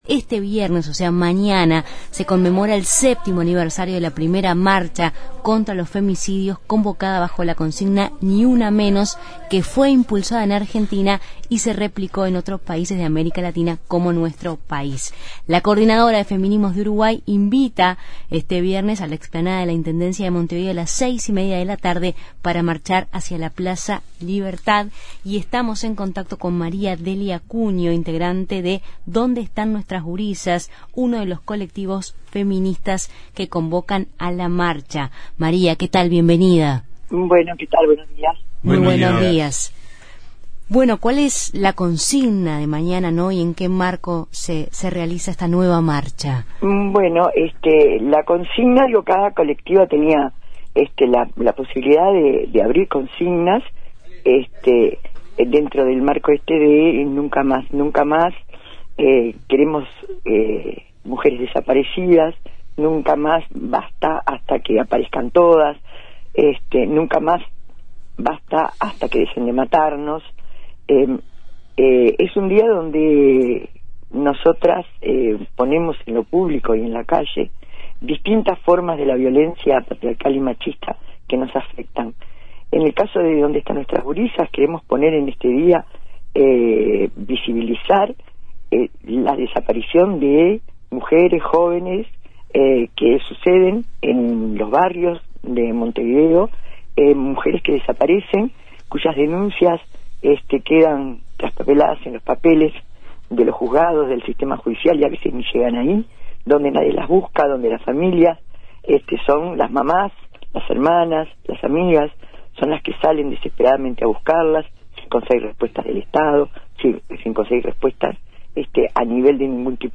Integrantes de organizaciones feministas explican los detalles y objetivos de esta nueva convocatoria